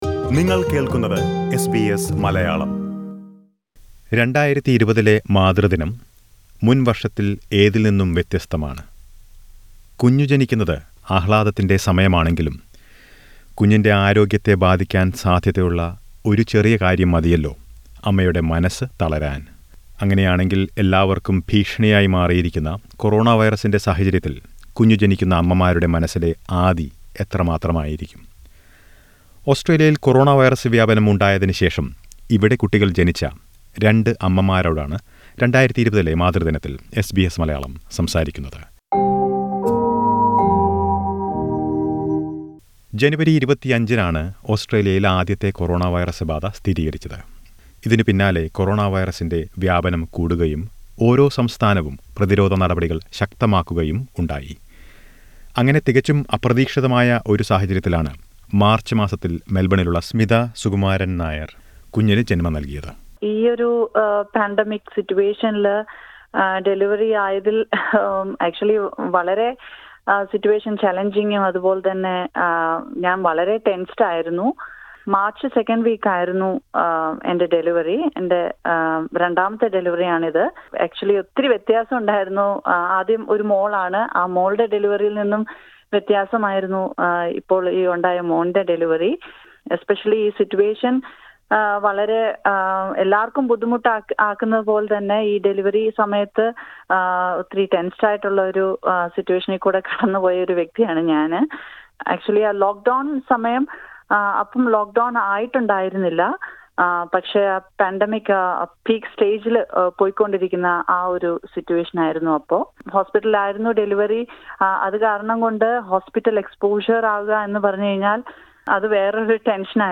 For pregnant women this could be days that could pose many a challenge. On Mothers Day two malayalee mums share their experiences of giving birth during these difficult times.